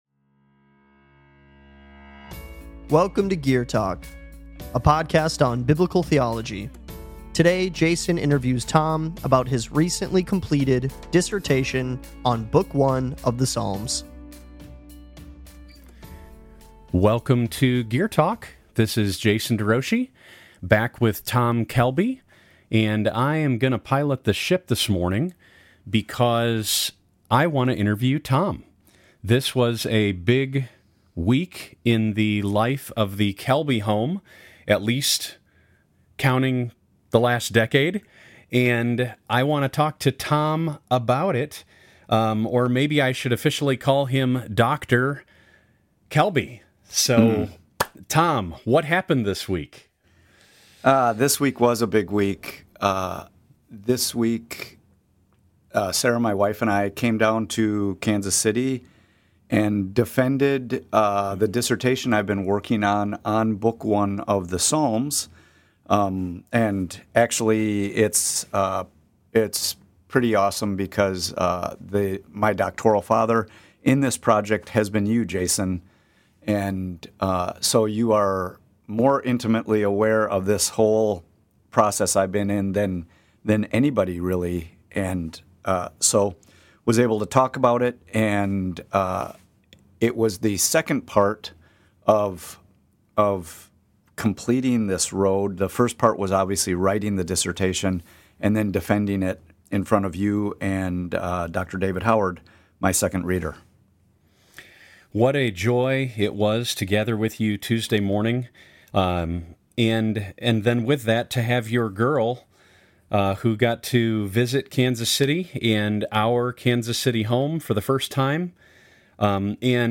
gear-talk-dissertation.mp3